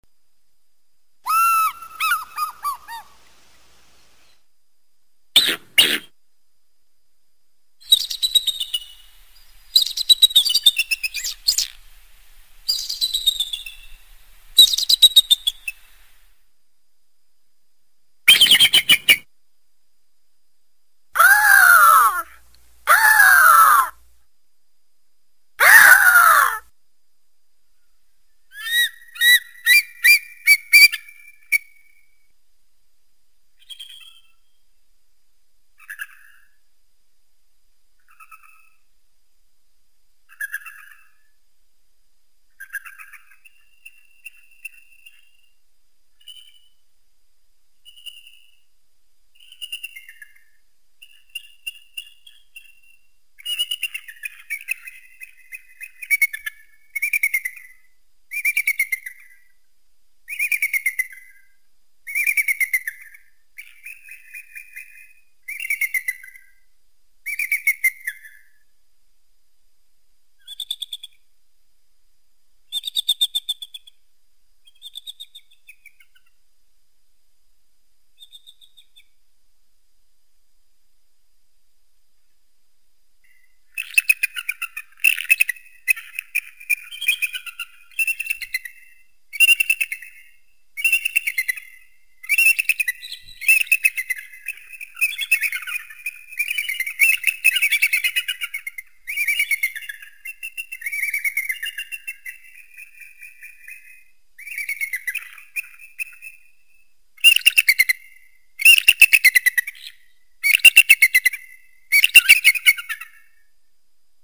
Tiếng động vật